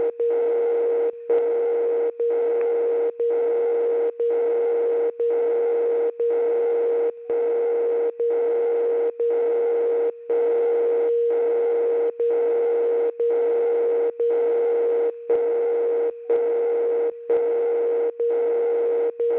Oprava:60 vteřina.
DCF77.wav